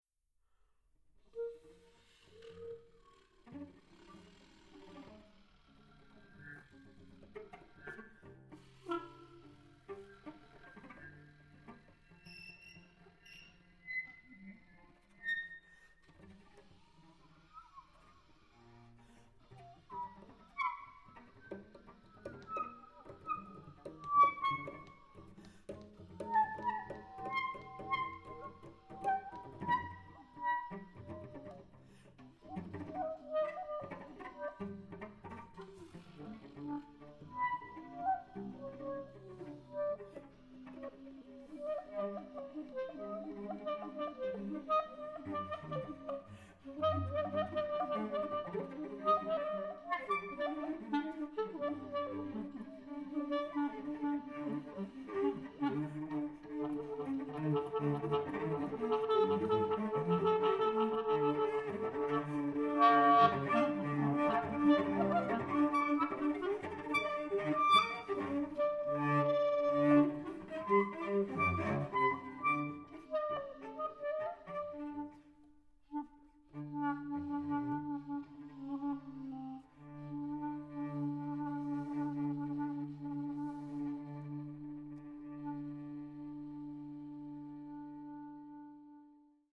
clarinet
cello